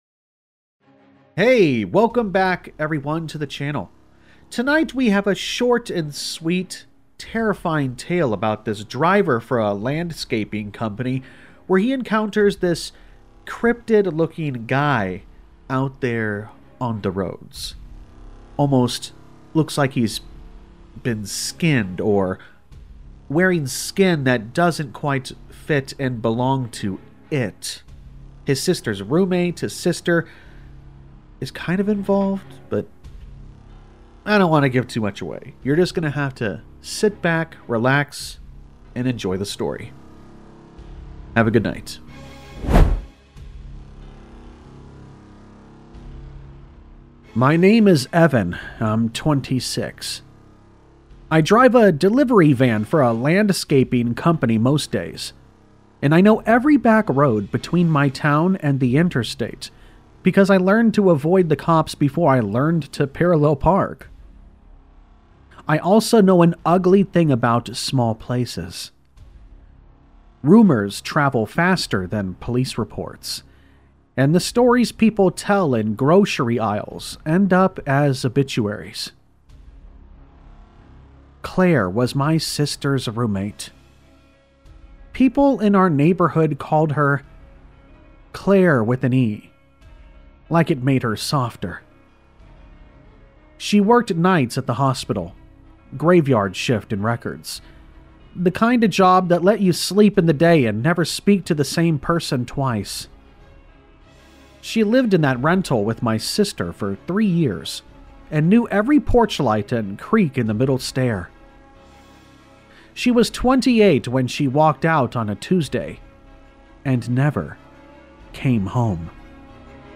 | Creepypasta Jan 17, 11:00 PM Headliner Embed Embed code See more options Share Facebook X Subscribe Late one night on a desolate stretch of Midwest highway, a road maintenance worker comes face to face with something that should not exist — the Skinned Man. This terrifying Creepypasta horror story takes you deep into the dark heart of the American Midwest, where true scary stories blur the line between urban legend and nightmare.
All Stories are read with full permission from the authors:
Sound Effects Credit